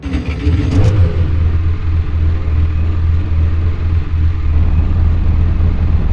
Index of /server/sound/vehicles/lwcars/lotus_esprit
startup.wav